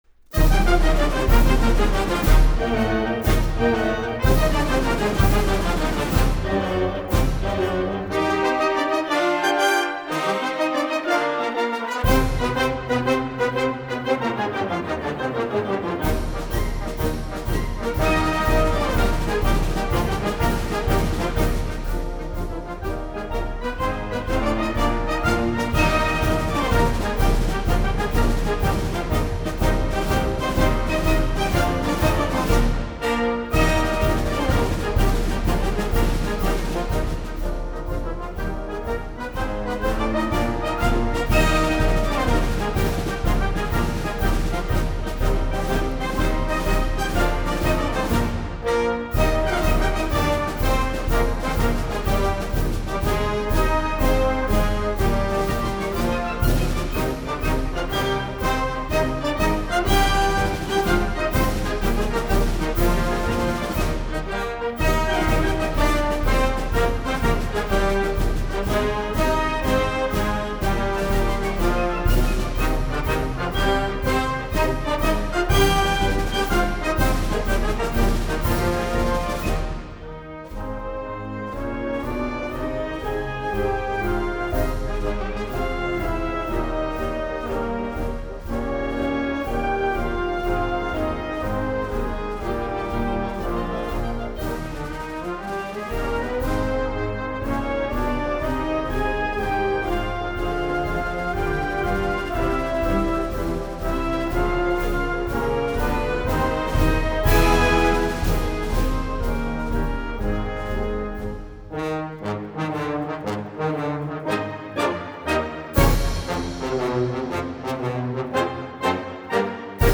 这是一张不吵的进行曲录音，它虽然没有办法换下您家中的1812炮声，